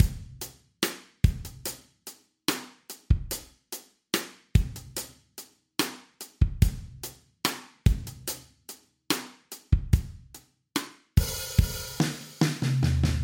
WORTH 鼓组 合唱
标签： 145 bpm Pop Loops Drum Loops 2.25 MB wav Key : Unknown Pro Tools
声道立体声